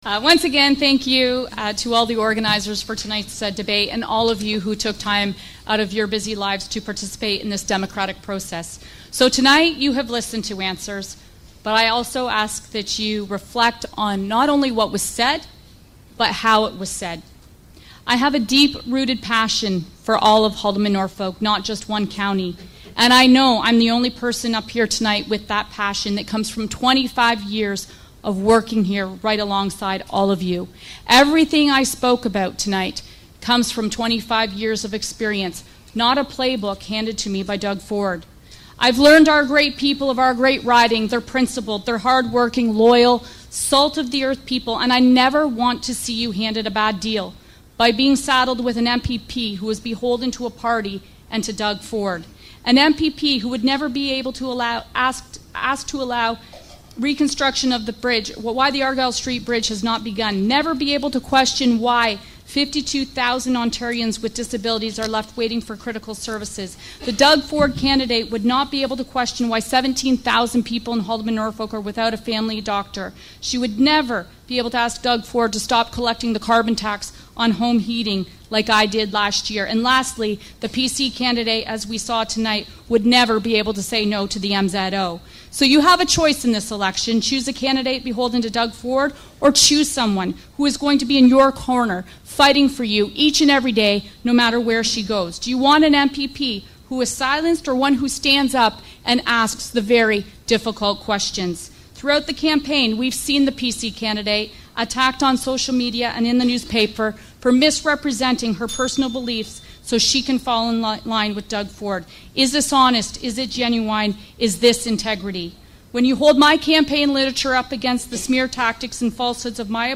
It seemed fitting that, with only one debate featuring a majority of the candidates being held, that we highlighted their closing statements from that night on the final day of the election campaign.